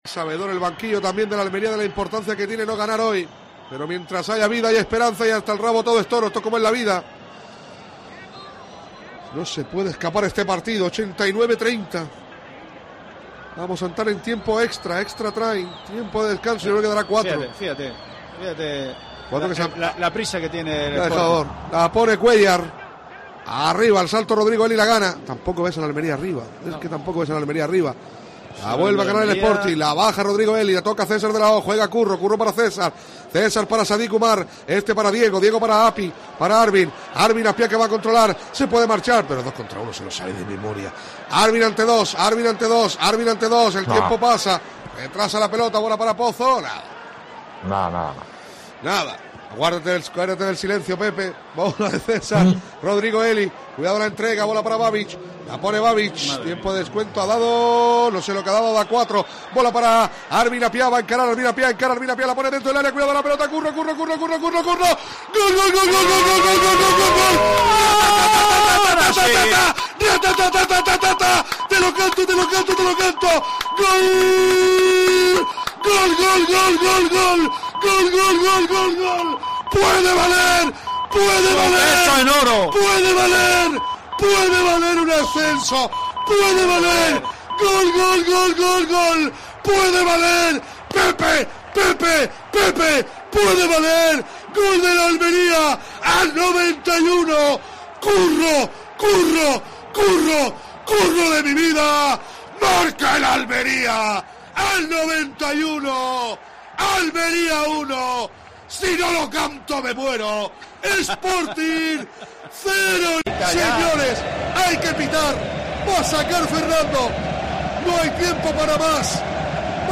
Triunfo agónico, en el minuto 90, del Almería para mantener su segundo puesto en la Liga SmartBank. Narración